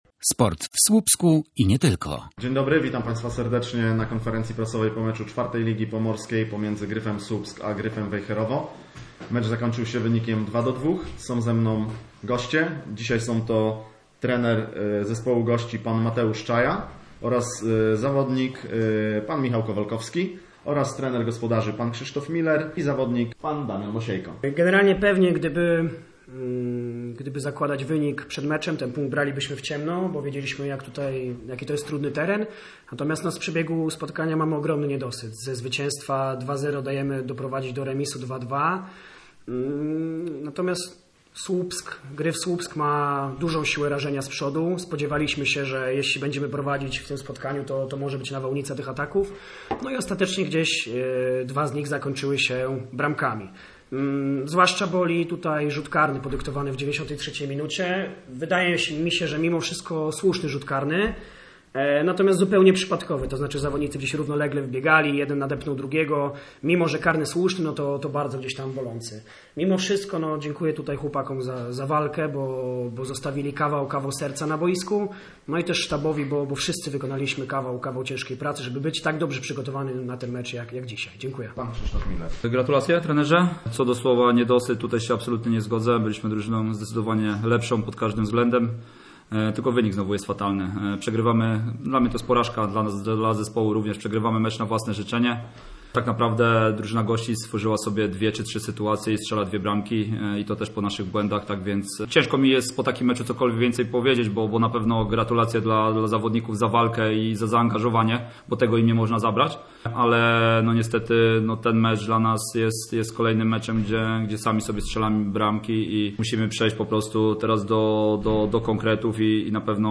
Posłuchaj pomeczowych wypowiedzi trenerów i zawodników Gryfa Słupsk i Gryfa Wejherowo: https